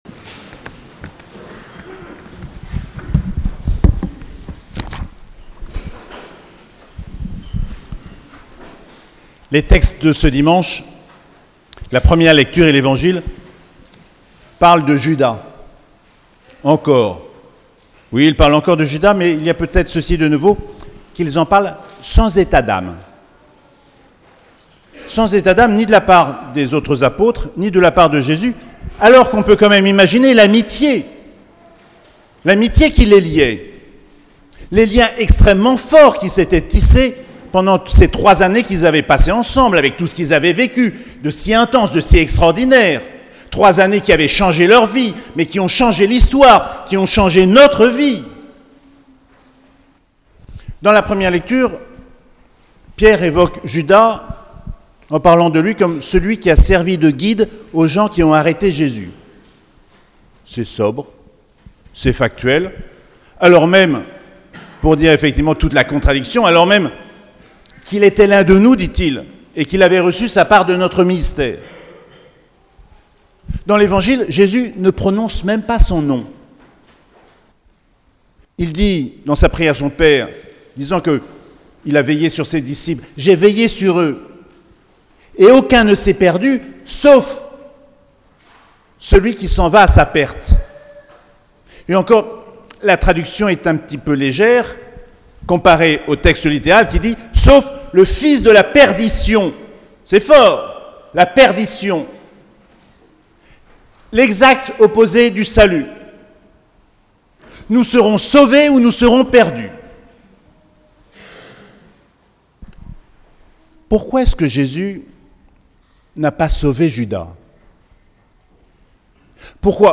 7ème dimanche du Pâques - 12 mai 2024